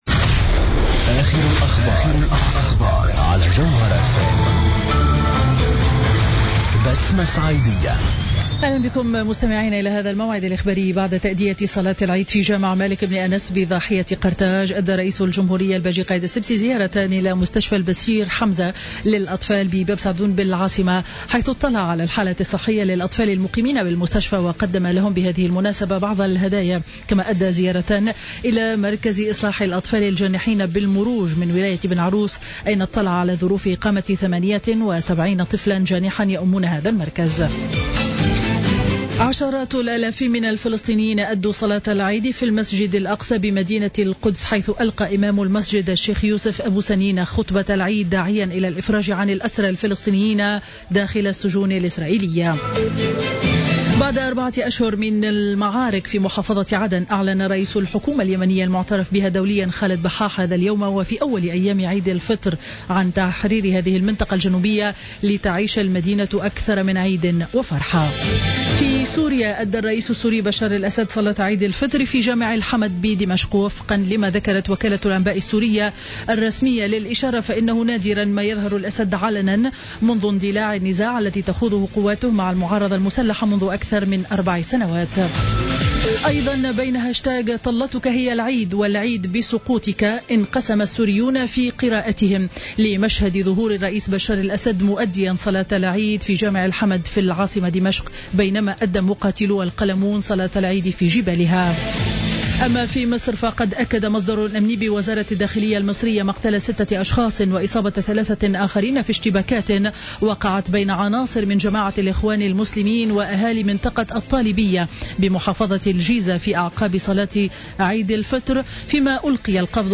نشرة أخبار السابعة مساء ليوم الجمعة 17 جويلية 2015